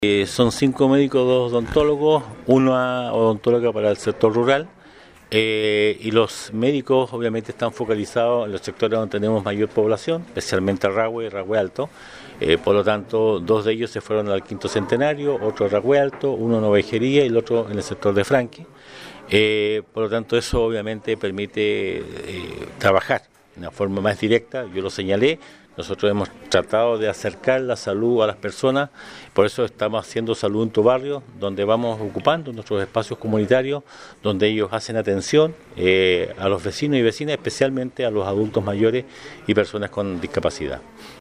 En un acto realizado en Sala de Sesiones, el municipio de Osorno dio la bienvenida a los cinco nuevos médicos y dos odontólogos que llegaron a trabajar a distintos Centros de Salud Familiar de la comuna, lo que es posible gracias al “Programa de Destinación y Formación”, del Ministerio de Salud.